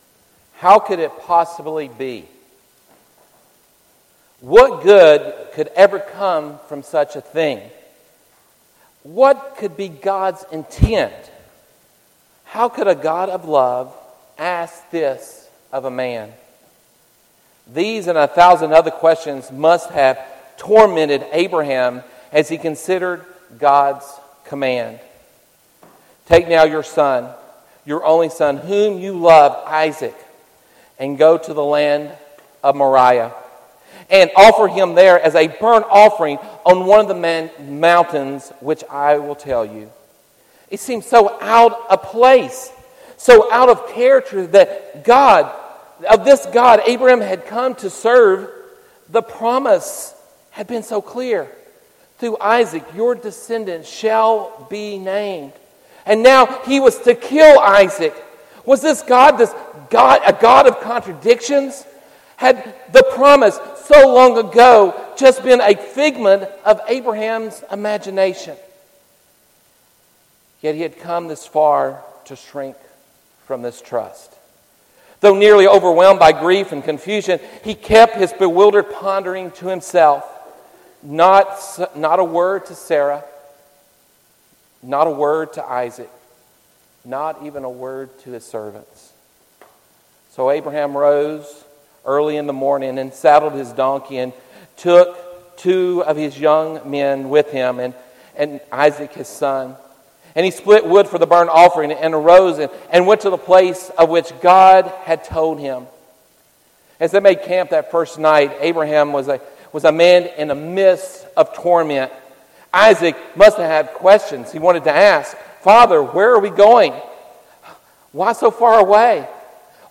Audio Sermons